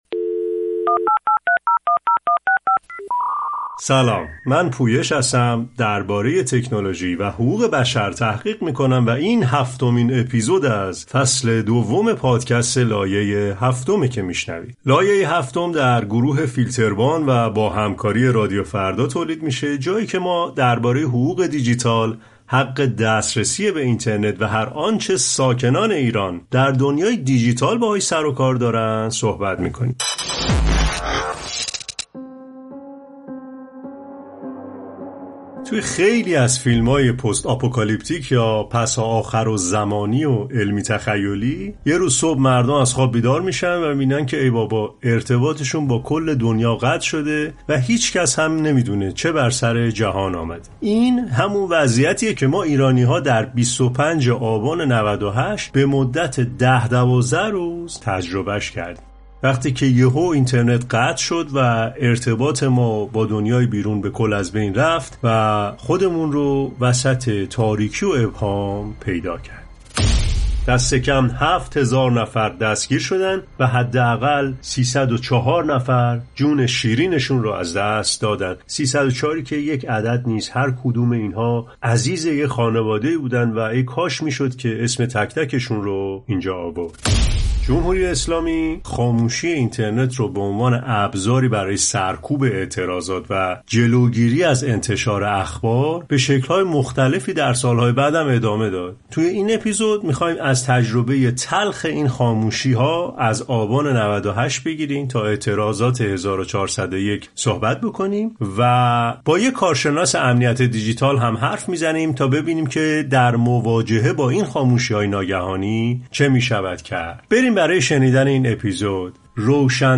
به بهانه سالگرد قطعی اینترنت آبان ۹۸، در این اپیزود، روایت‌هایی از تجربه تلخ قطع اینترنت در ایران، از آبان ۹۸ تا مهاباد ۱۴۰۱ را می‌شنویم. همچنین، با یک متخصص امنیت دیجیتال هم‌صحبت شدیم تا راه‌های مقابله با سانسور و قطعی اینترنت را با هم مرور کنیم.